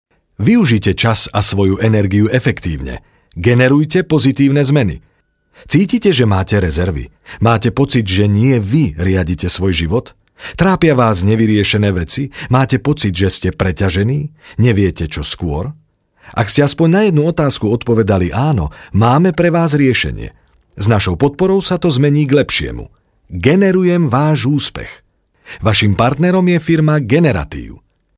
slowakischer Sprecher
Sprechprobe: Werbung (Muttersprache):
slovakian voice over talent